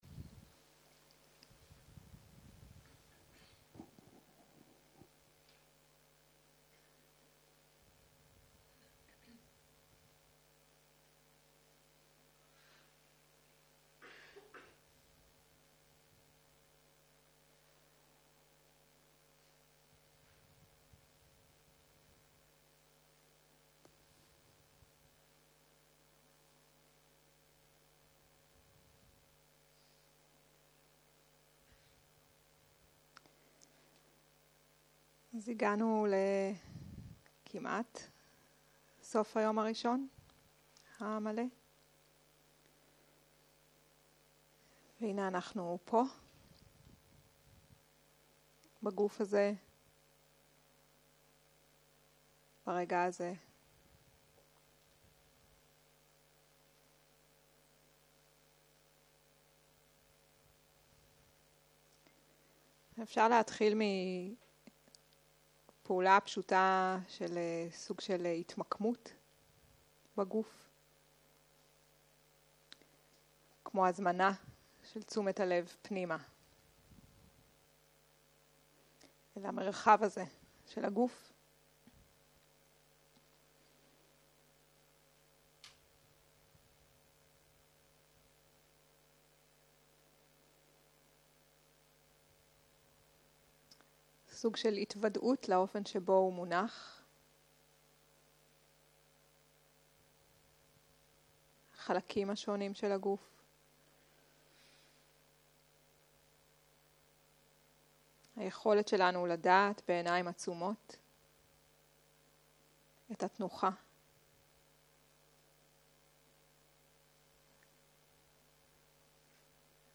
יום 2 - ערב - מדיטציה מונחית - הקלטה 4
Dharma type: Guided meditation